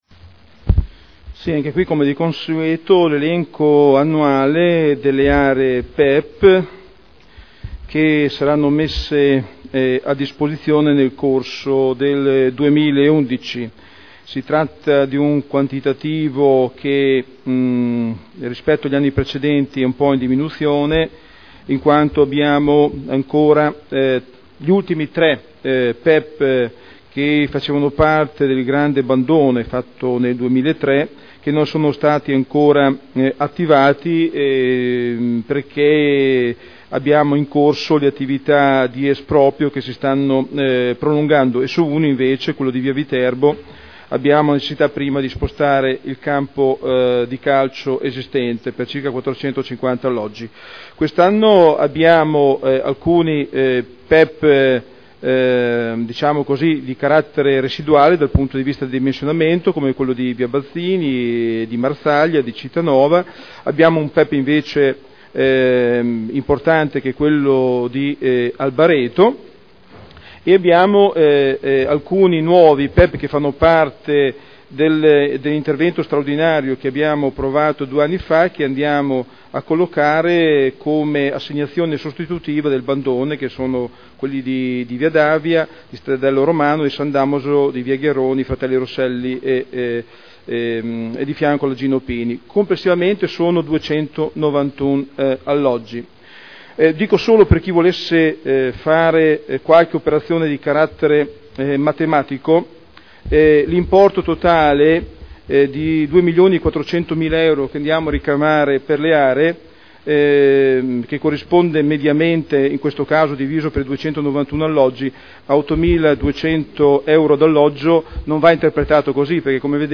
Seduta del 28/03/2011.